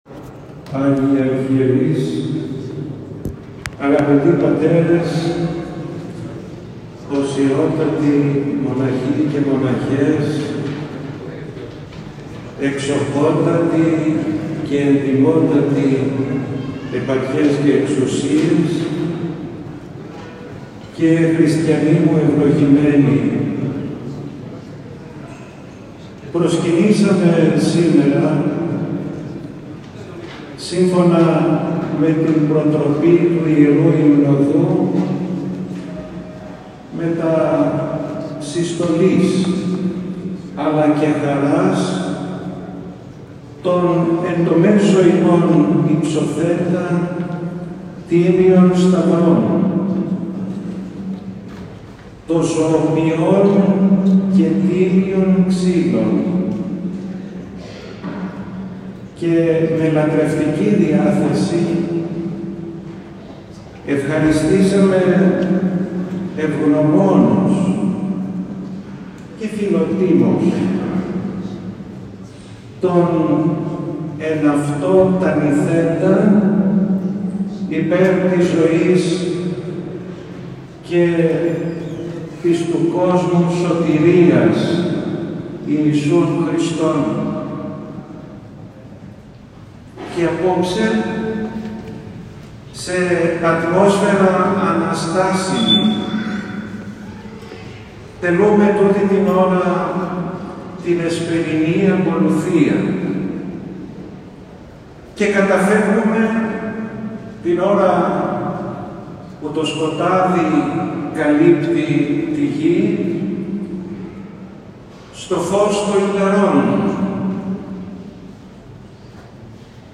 Δεκάδες πιστοί συμμετείχαν το απόγευμα στον Πανηγυρικό Εσπερινό στον Ιερό Μητροπολιτικό Ναό Αγίου Γρηγορίου Παλαμά Θεσσαλονίκης, για να ευχηθούν στον Μητροπολίτη Θεσσαλονίκης κ. Φιλόθεο, ο οποίος αύριο -ημέρα κατά την οποία η Εκκλησία τιμά τη μνήμη του Οσίου Φιλοθέου– άγει τα ονομαστήριά του.
Τον Θείο Λόγο κήρυξε ο Μητροπολίτης Χαλκίδος, ο οποίος ευχήθηκε στο πνευματικό του ανάστημα και εξήρε το σημαντικό έργο που επιτελεί στην τοπική Εκκλησία. Ακούστε την ομιλία του Μητροπολίτη Χαλκίδος κ. Χρυσοστόμου: